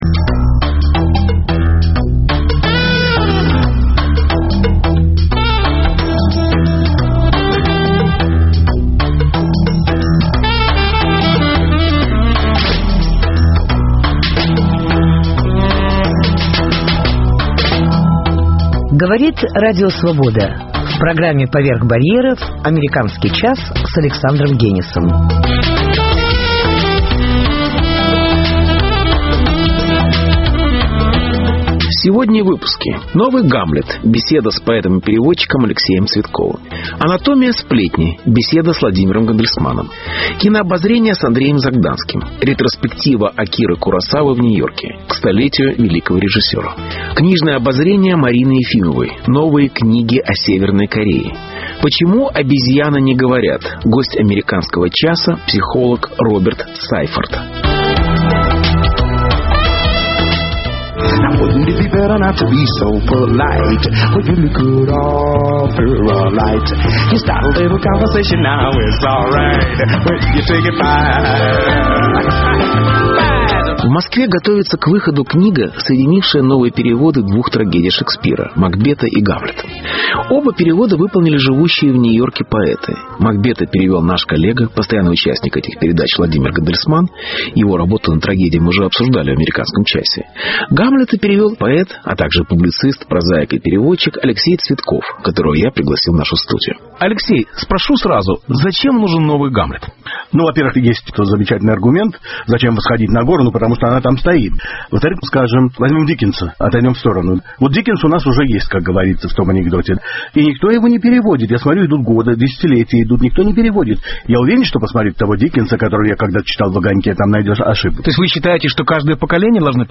Беседа с поэтом и переводчиком Алексеем Цветковым.